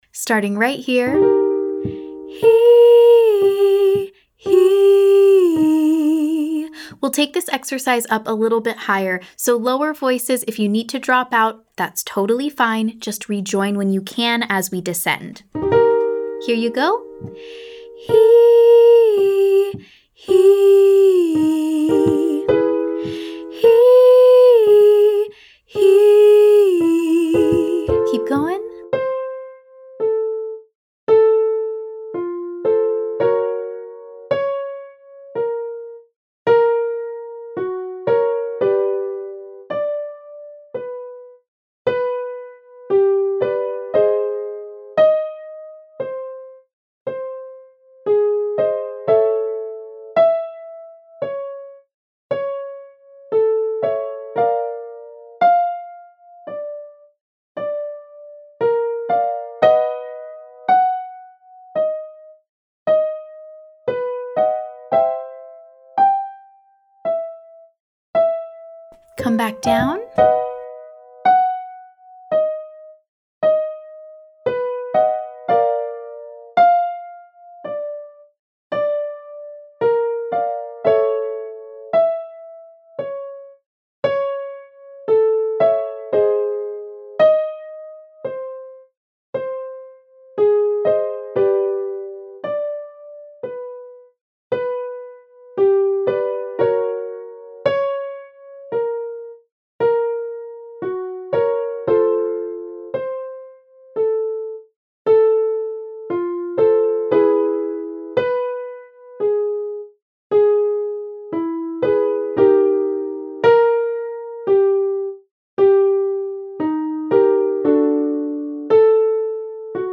Day 10: Light Head Voice (Falsetto)
Notice we’re sliding or falling from pitch to pitch, because oftentimes in pop music when you hear falsetto, you also hear smooth, flowy phrasing.
Exercise 1: HEE/HOO 5-3, 3-1 (sliding)